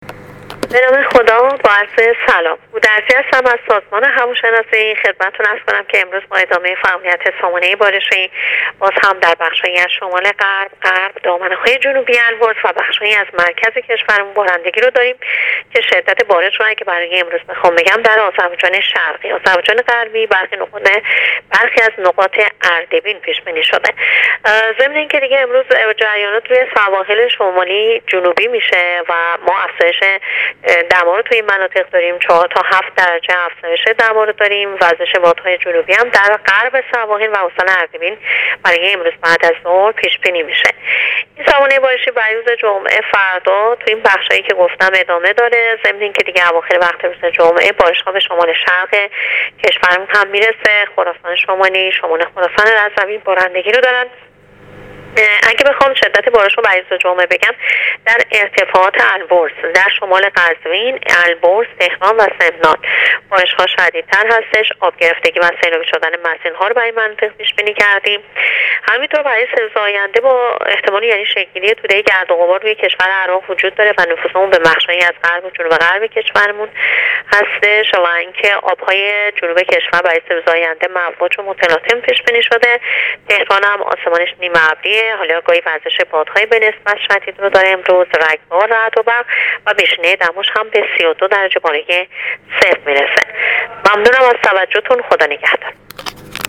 رادیو اینترنتی پایگاه خبری وزارت راه و شهرسازی: